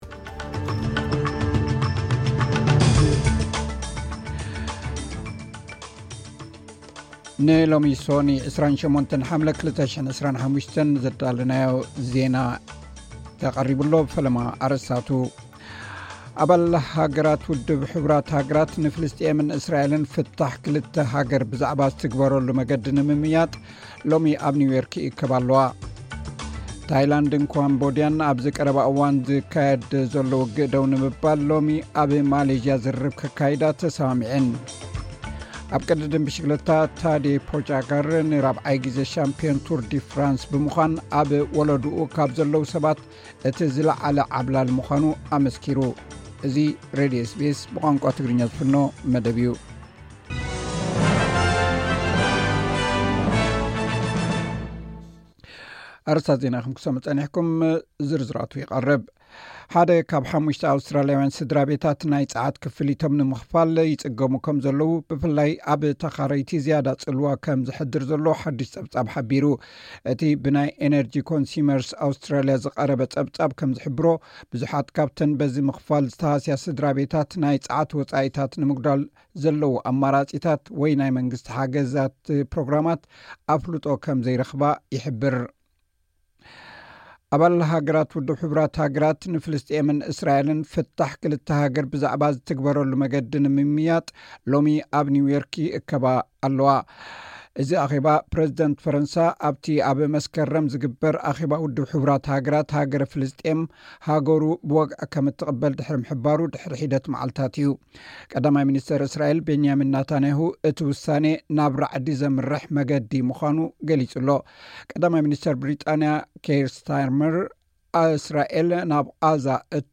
ዕለታዊ ዜና ኤስ ቢ ኤስ ትግርኛ (28 ሓምለ 2025)